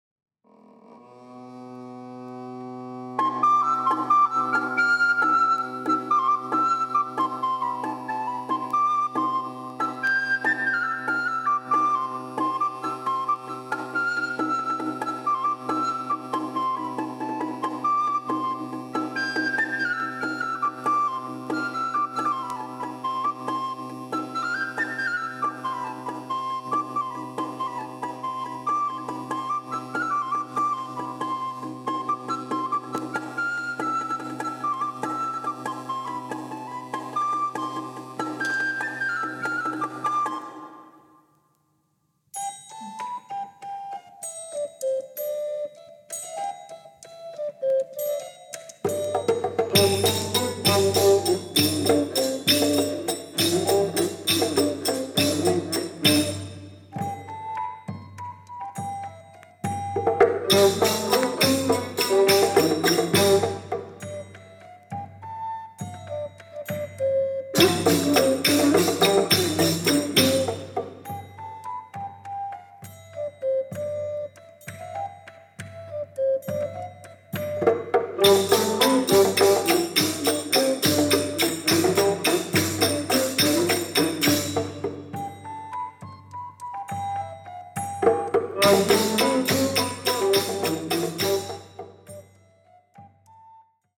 Title : Atrium Musicae De Madrid
中世の詩人・ティボーの旋律を素材にした幻想絵巻。